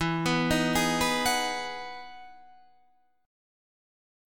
E Major 9th